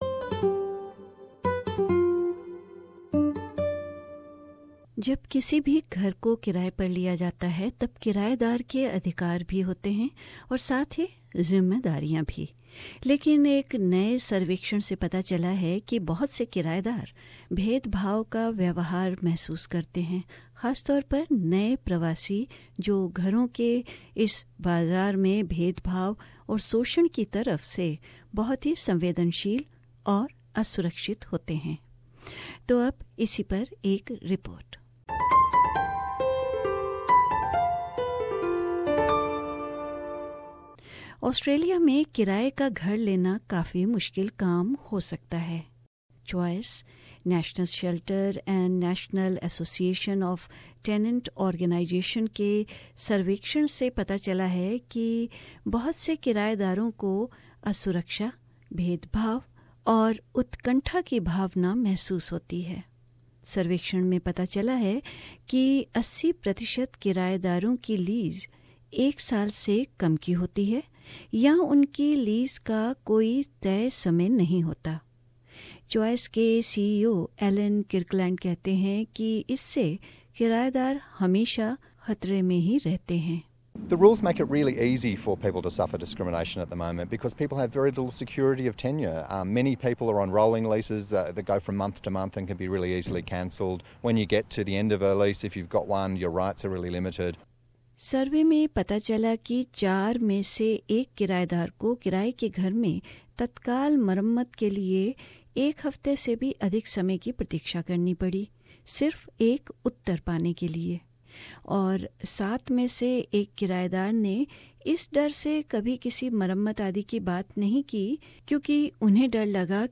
NSW Fair Trading कमिशनर श्री रॉड स्टो कहते हैं कि वह सामुदायिक संगठनों के साथ मिलकर काम कर रहे हैं और इसी सिलसिले में एक विडियो तैयार किया है - Renting a home: a tenant's guide to rights and responsibilities.